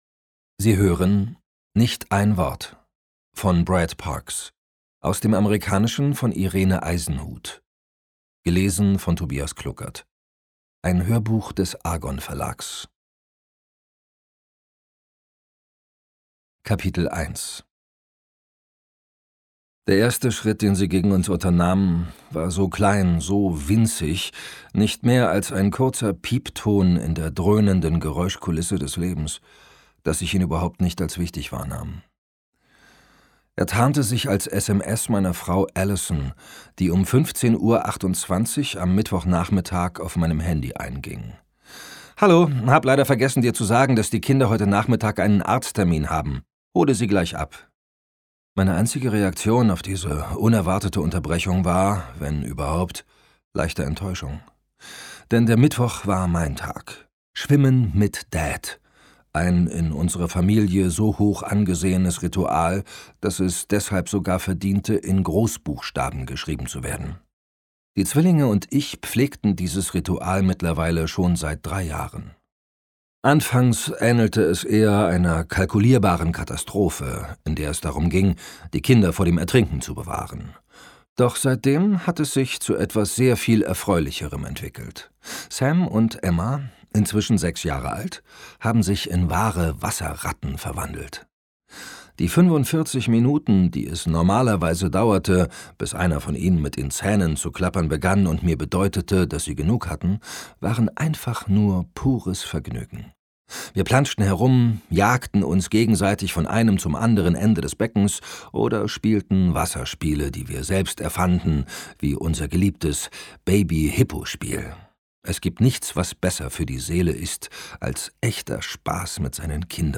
2018 | 2. Auflage, Gekürzte Ausgabe